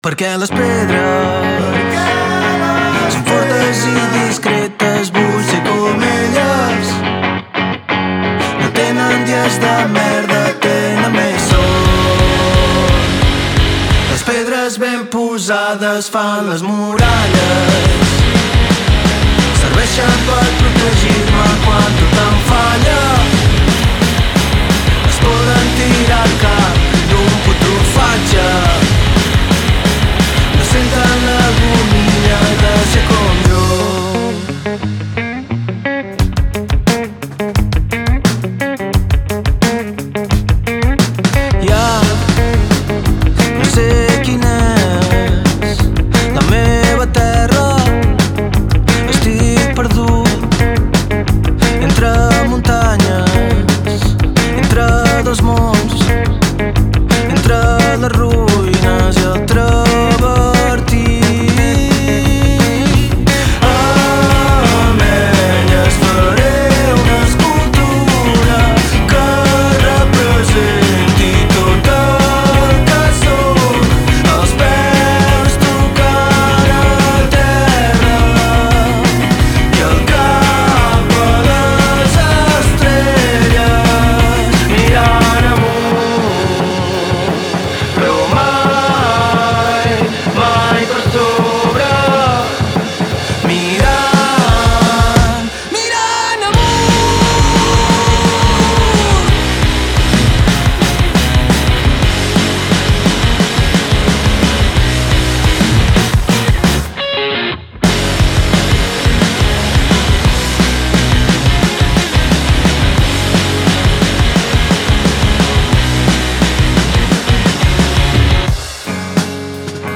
Genres: Indie Rock, Alternative Rock